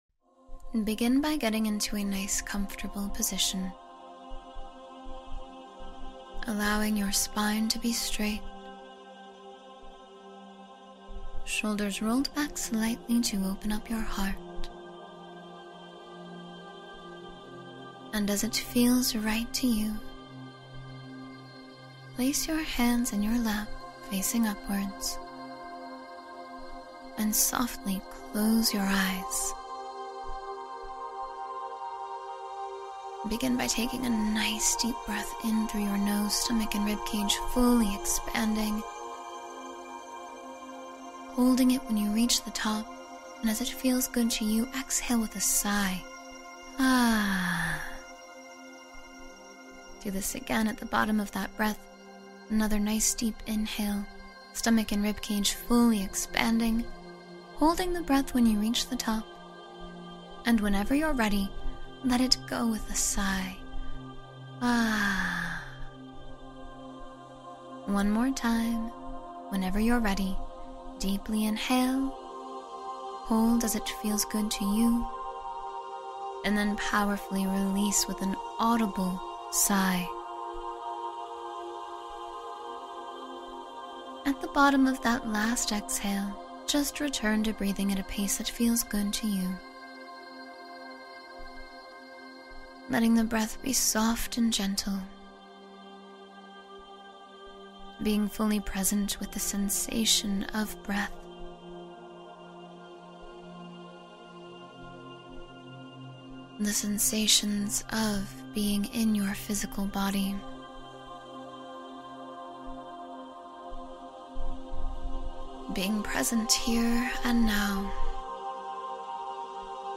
“I Am” Affirmations for Positive Change — Guided Meditation for Confidence